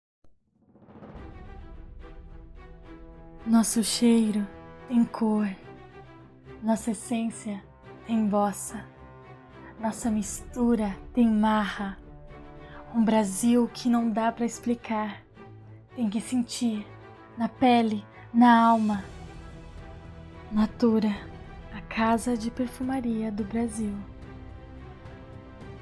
Feminino
Comercial, Publicidade, Natura - Demo
Voz Jovem 00:25